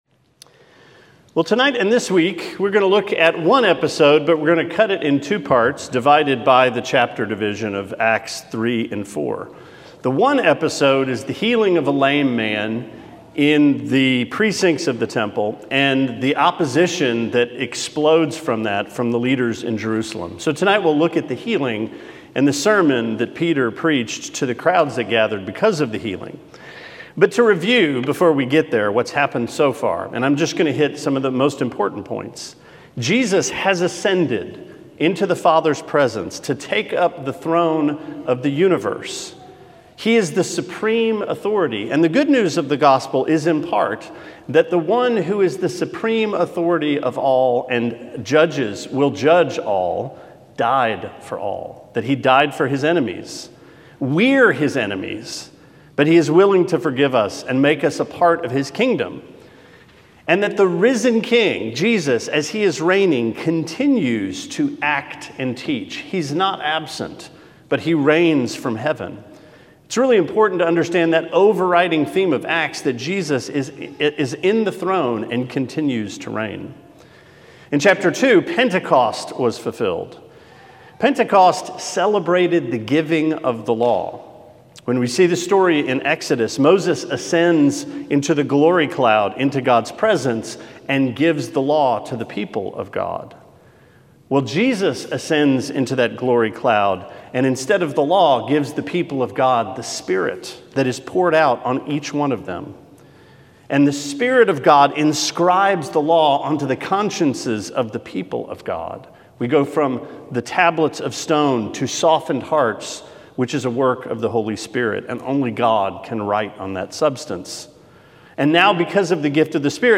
Sermon 8/29: Acts 3: A Bigger Gospel